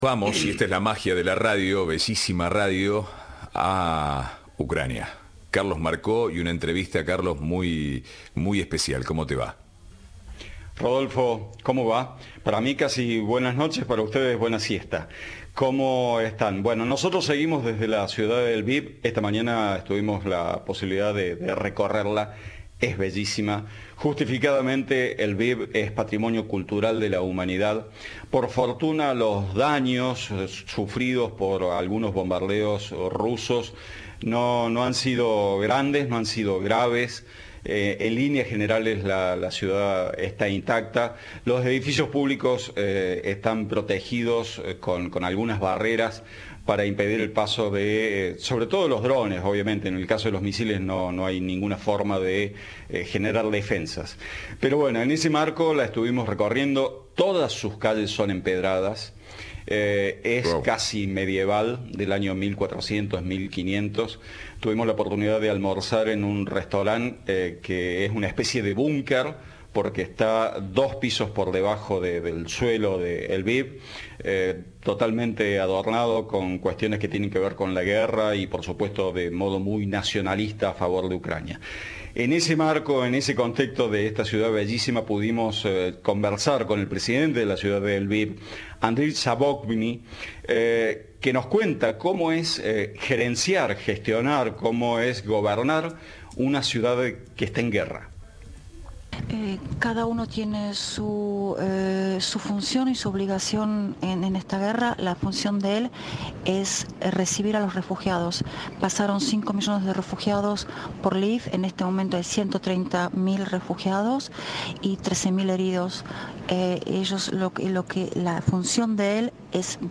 El presidente y máxima autoridad de la ciudad ucraniana de Lviv dijo a Cadena 3 que su obligación en la invasión rusa a su país es la de recibir a los refugiados y a los heridos.